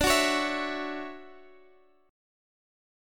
Listen to DM9 strummed